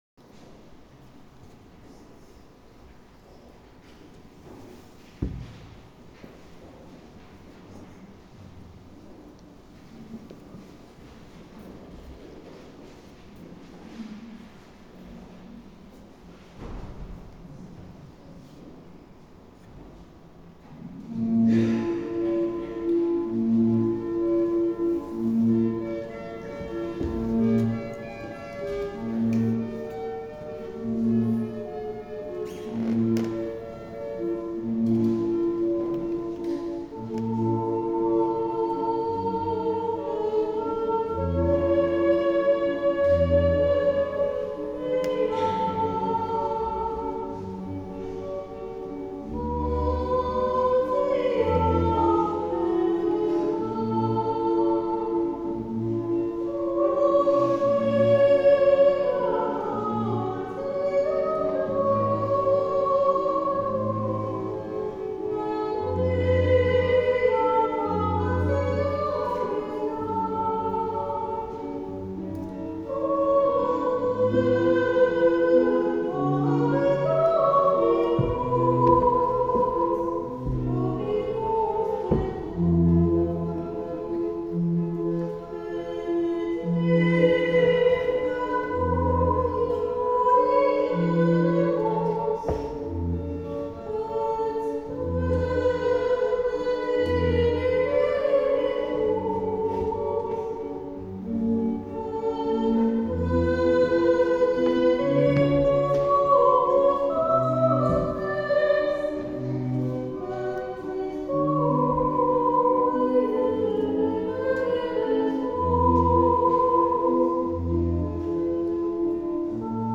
En sons et en images… quelques prises lors de célébrations – Voix d'Espérance